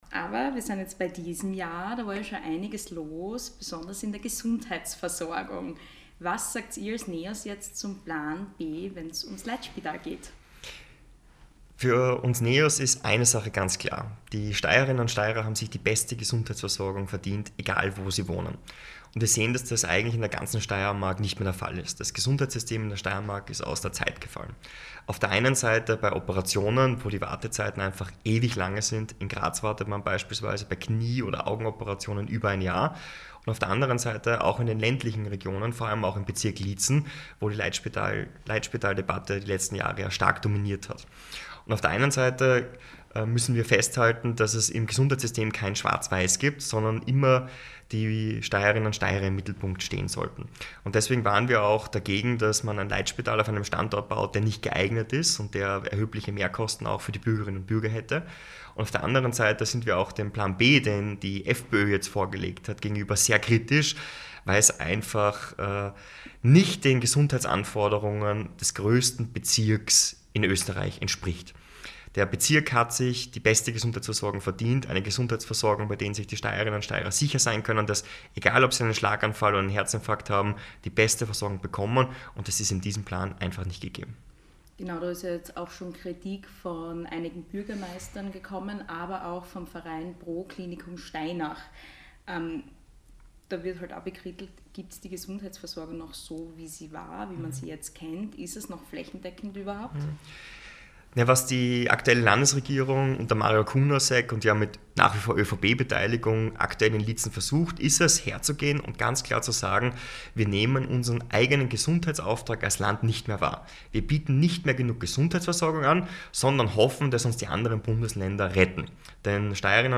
Auch in diesem Sommer spricht unser Landessprecher Niko Swatek in Interviews Klartext.
Soundportal Sommergespräch 2025.mp3